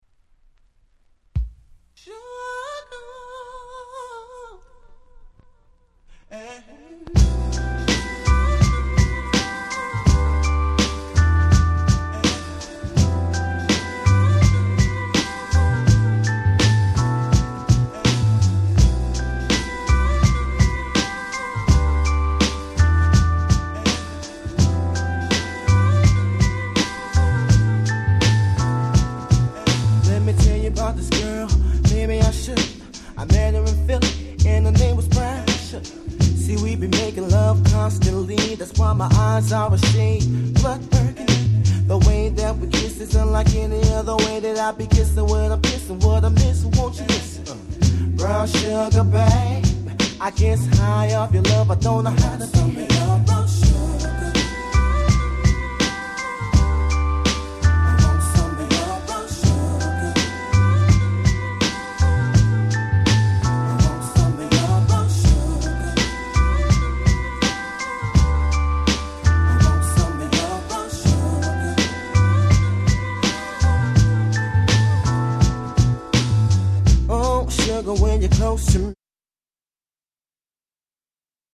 Nice Neo Soul♪